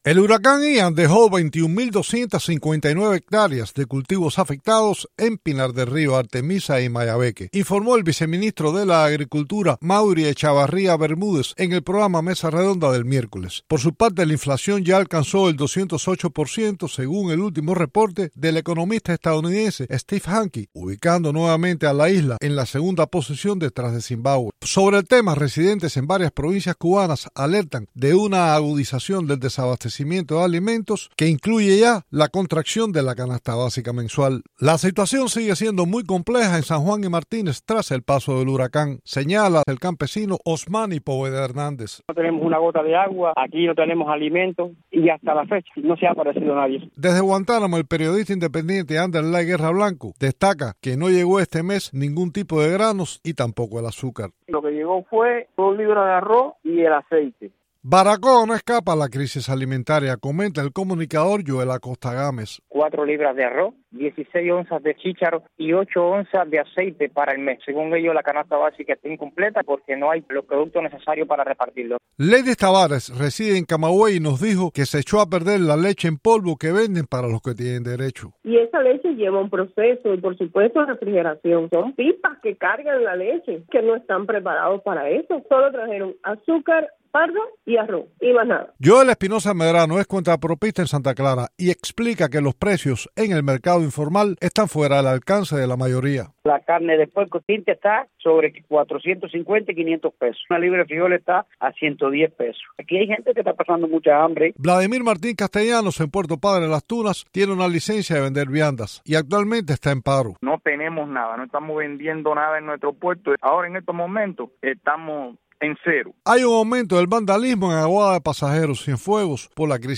entrevistó a cubanos en varias localidades de la isla